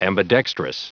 Prononciation du mot ambidextrous en anglais (fichier audio)
Prononciation du mot : ambidextrous